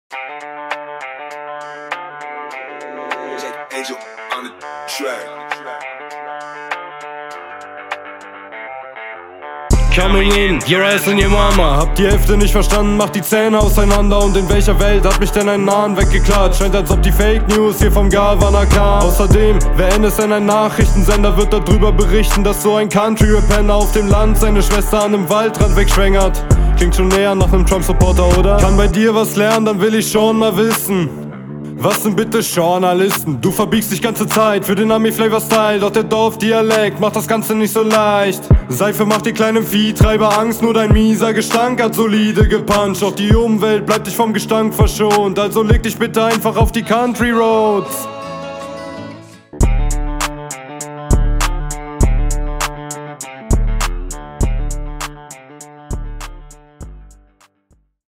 Leider scheiße geflowt, textlich aber eindeutig überlegen.
Auf dem Mix ist glaube ich viel zu viel Reverb.